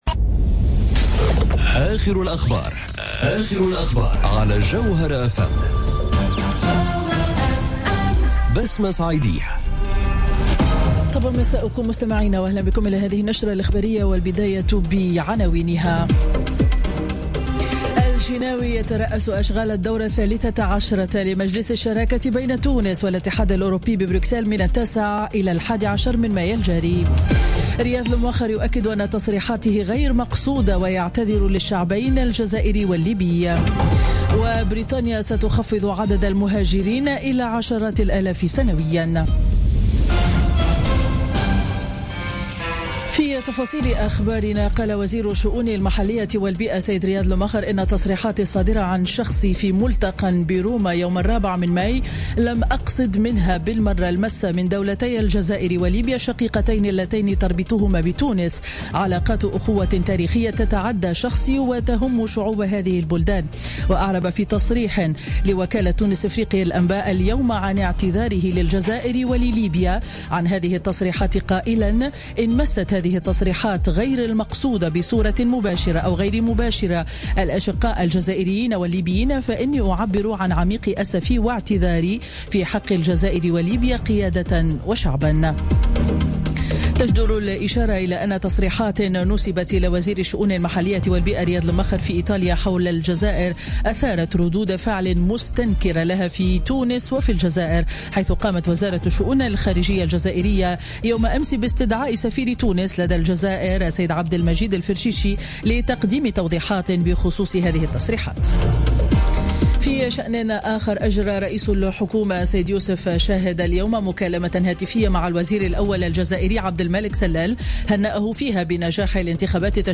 نشرة أخبار السابعة مساء ليوم الاثنين 8 ماي 2017